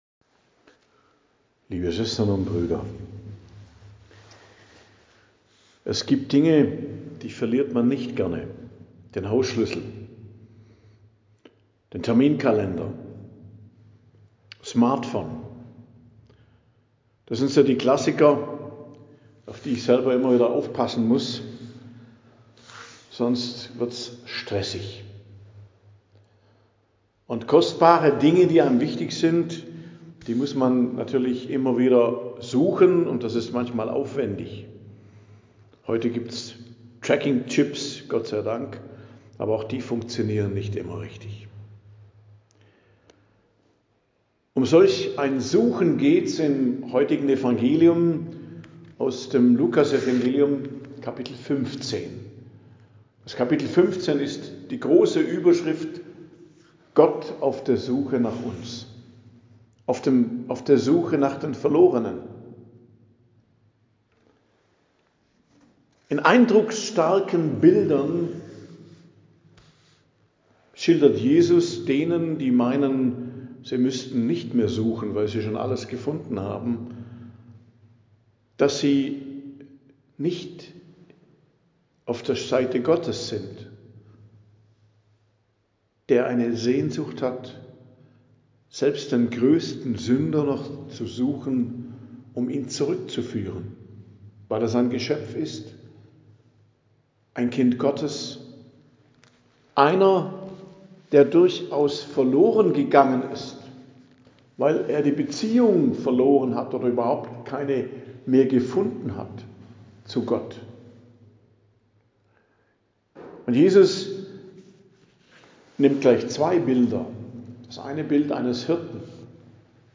Predigt am Donnerstag der 31. Woche i.J., 6.11.2025